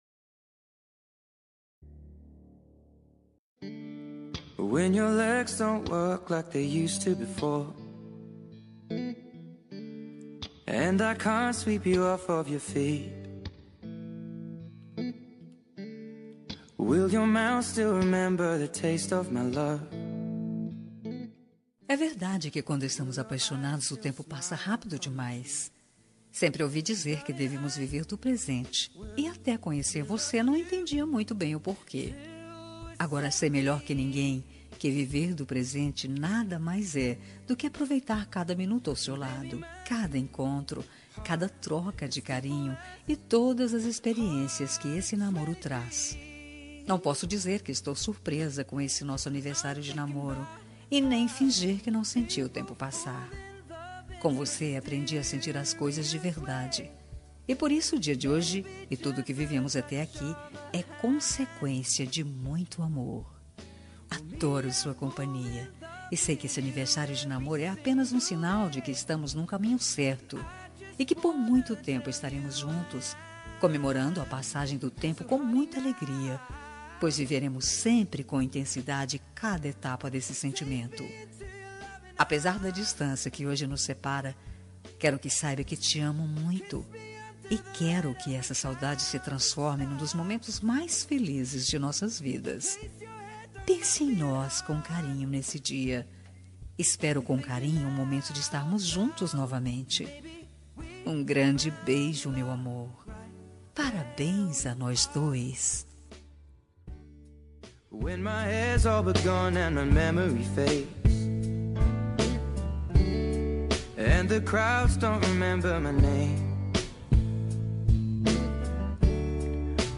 Aniversário de Namoro – Voz Feminina – Cód: 01750 – Distante